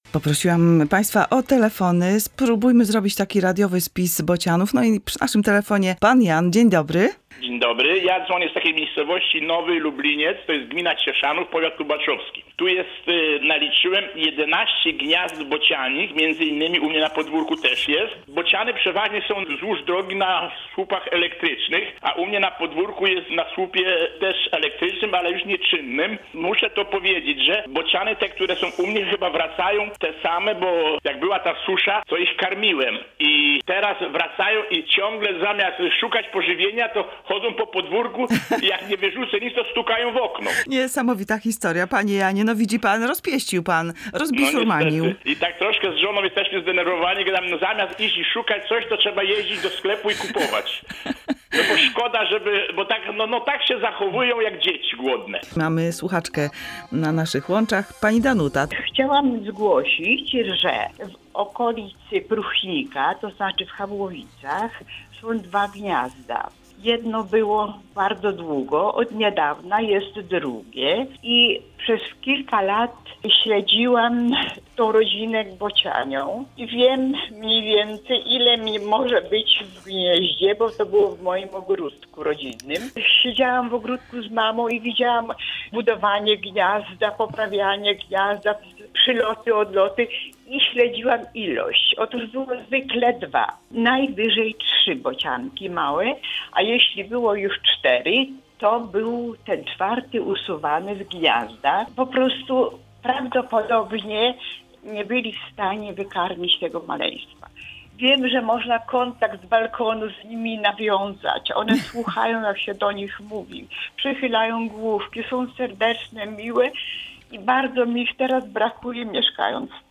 Polacy darzą bociany ogromną sympatią, o czym świadczą wypowiedzi Słuchaczy w naszej audycji „Wolna Sobota”.